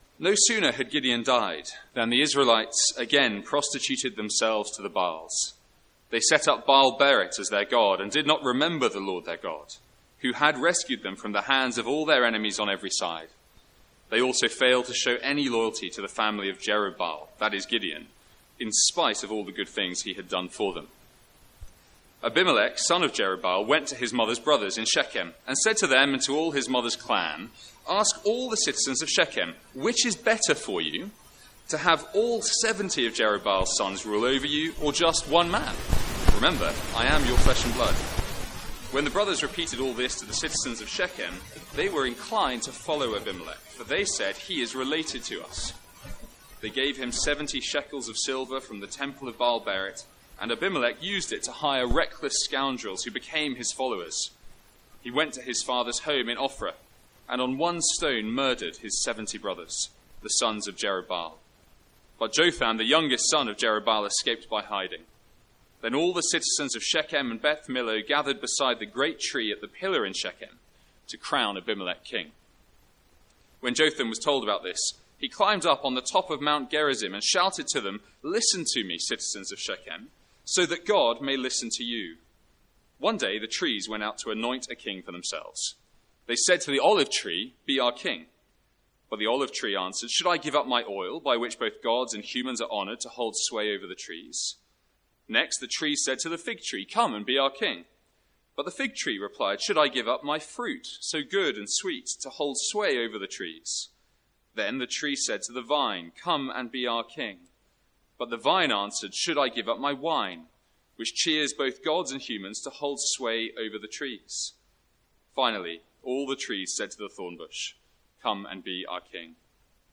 Sermons | St Andrews Free Church
From the Sunday morning series in Judges.